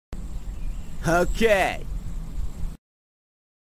Okay sound effect
Ok ok… sound effect Ok Ok…Okay Okay… (girl) sound effect
Thể loại: Âm thanh meme Việt Nam
okay-sound-effect-www_tiengdong_com.mp3